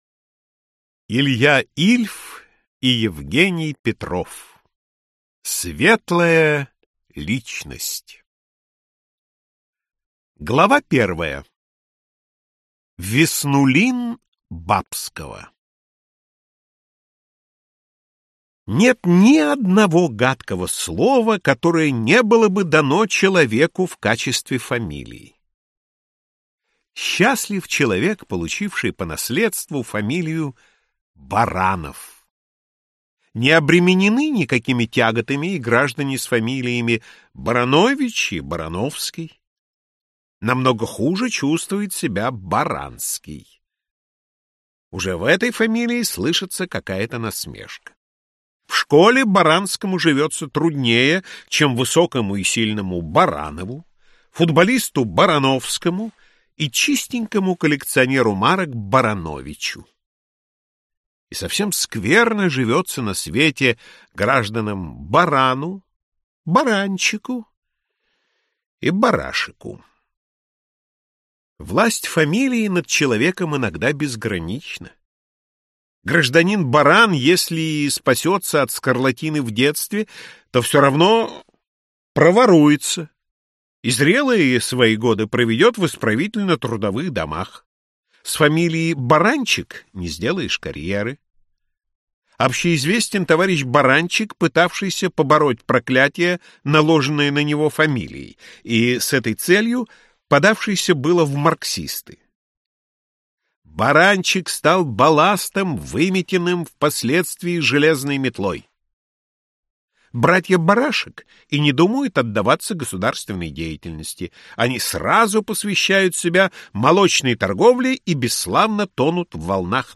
Аудиокнига Светлая личность - купить, скачать и слушать онлайн | КнигоПоиск
Аудиокнига «Светлая личность» в интернет-магазине КнигоПоиск ✅ Классика в аудиоформате ✅ Скачать Светлая личность в mp3 или слушать онлайн